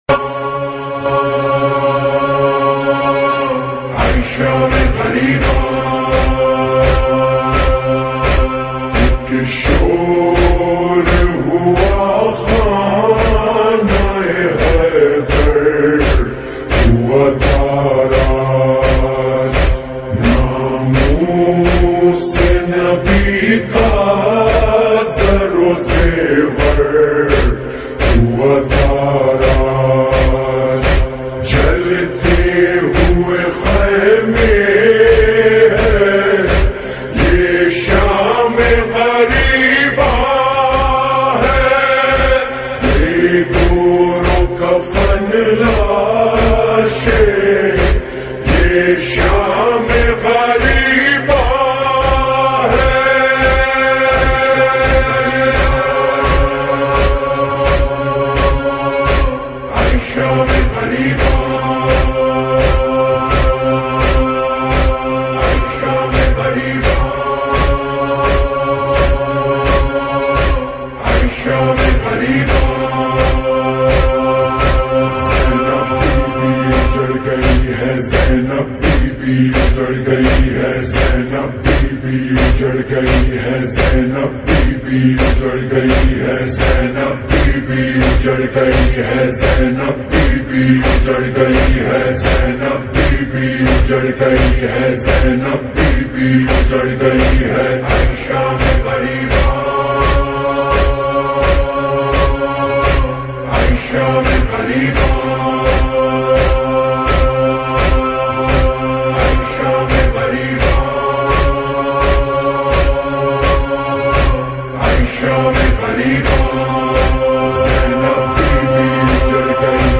Noha
Slowed + ReVeRb